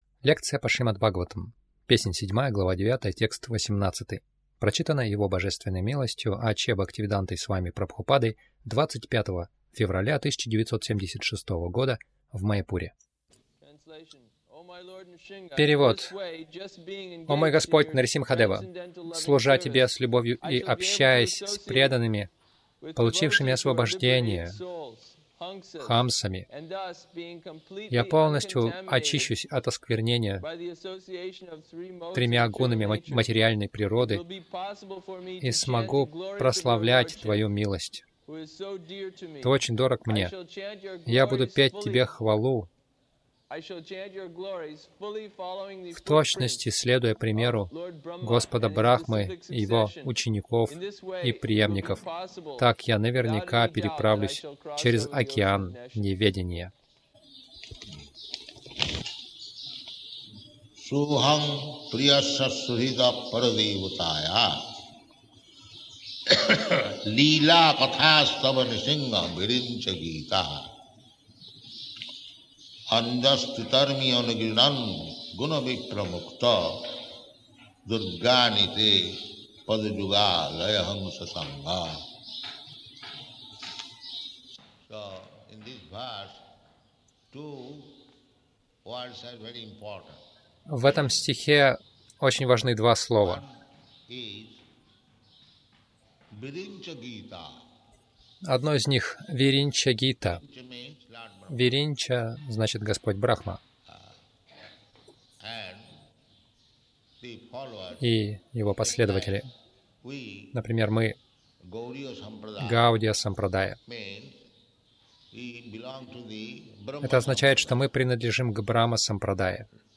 Милость Прабхупады Аудиолекции и книги 25.02.1976 Шримад Бхагаватам | Маяпур ШБ 07.09.18 — Слушайте только катху хамс — истинных преданных Загрузка...